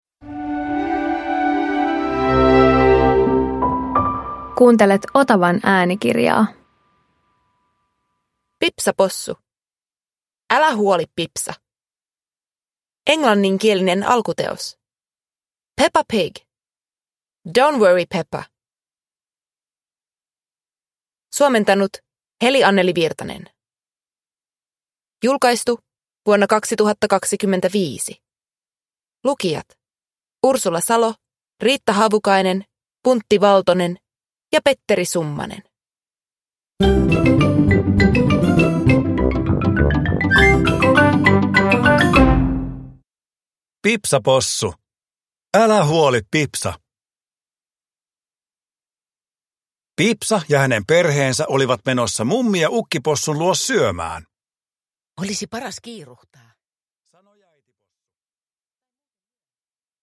Pipsa Possu - Älä huoli, Pipsa – Ljudbok